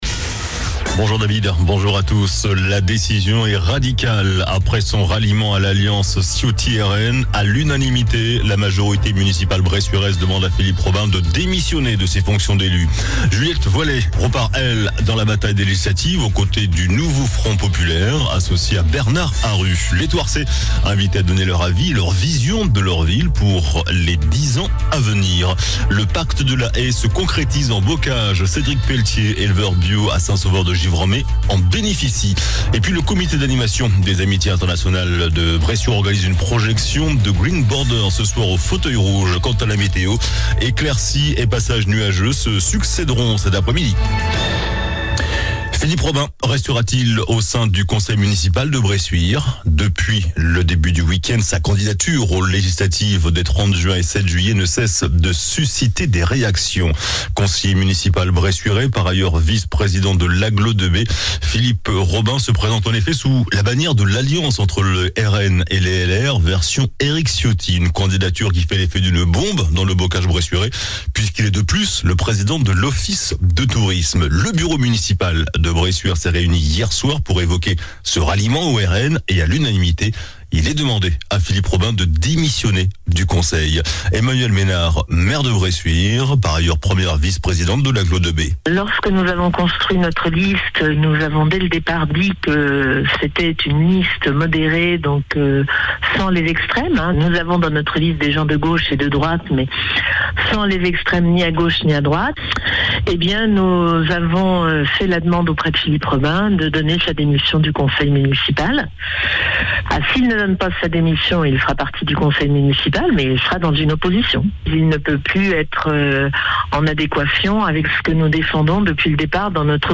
JOURNAL DU MARDI 18 JUIN ( MIDI )